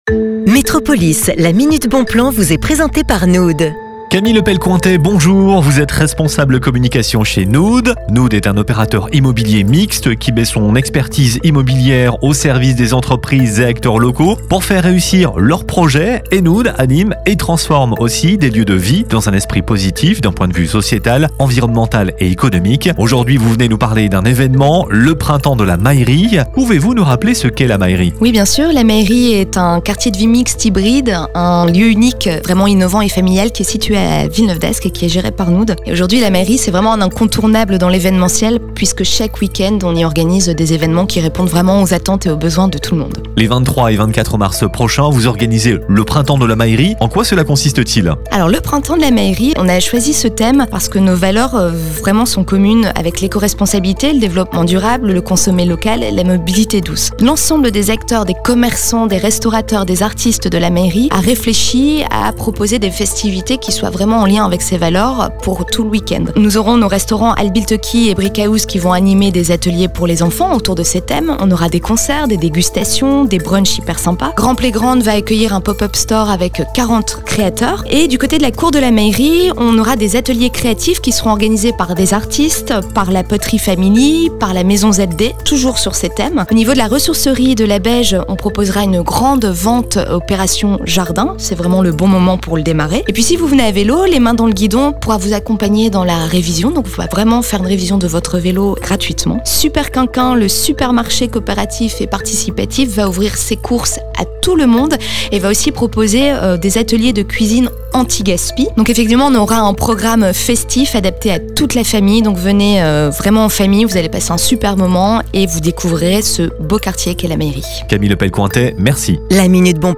Retrouvez le programme complet dans cette interview